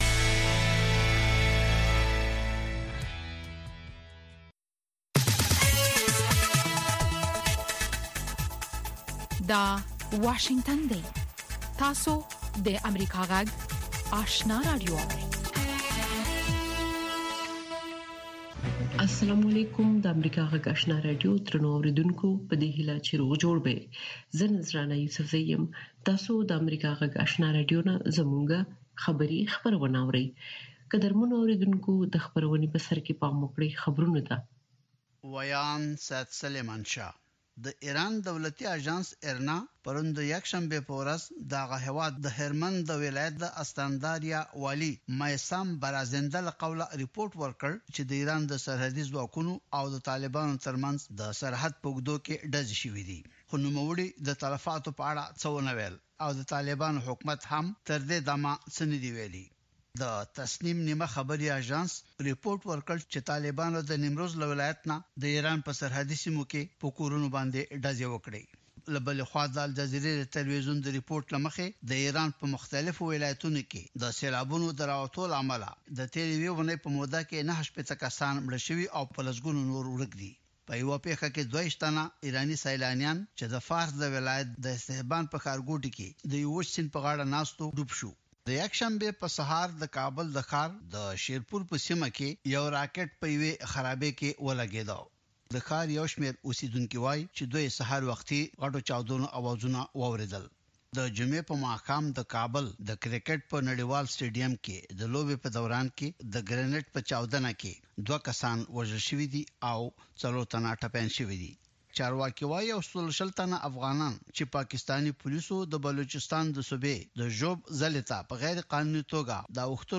سهارنۍ خبري خپرونه
په دې نیم ساعته خپرونه کې د افغانستان او نړۍ تازه خبرونه، مهم رپوټونه، مطبوعاتو ته کتنه او مرکې شاملې دي.